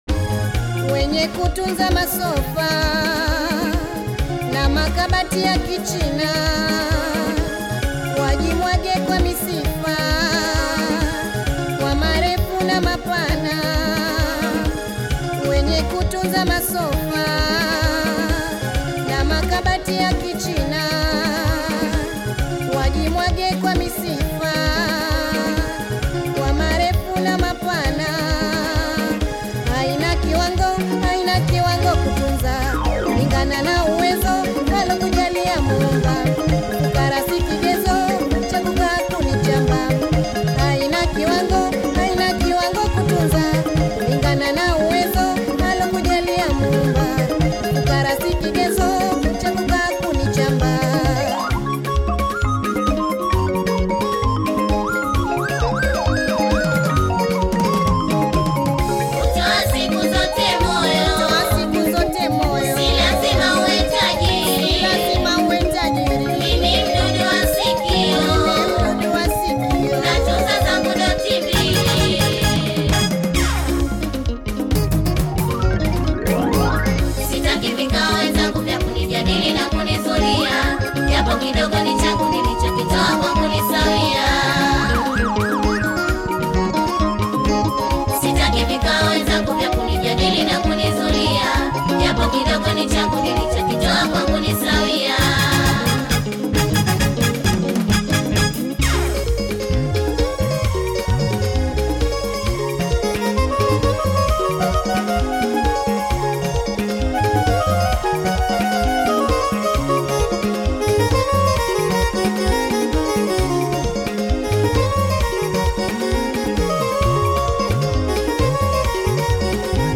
Experience the rich Swahili rhythms and poetic vocals